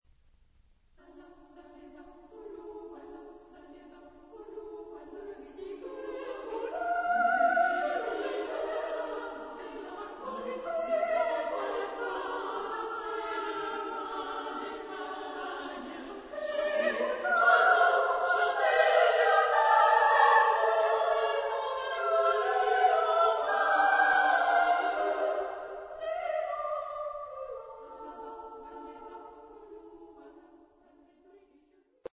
Genre-Style-Forme : Chanson ; Profane